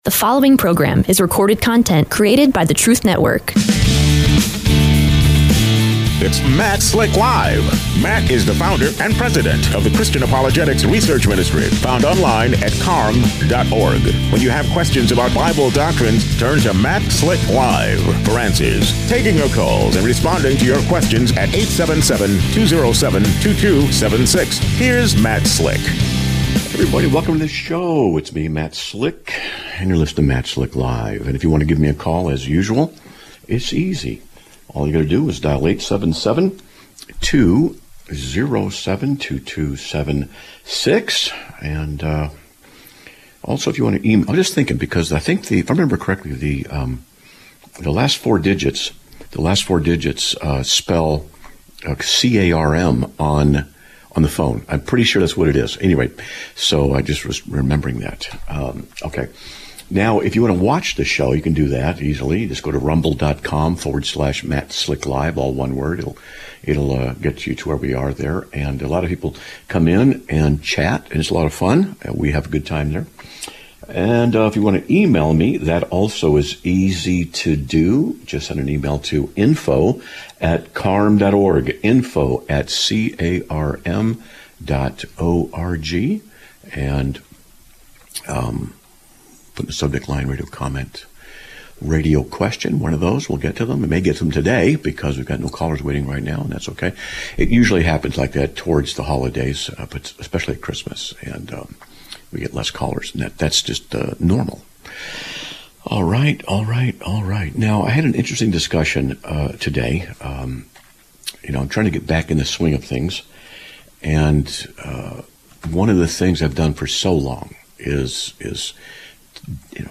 Live Broadcast of 12/11/2025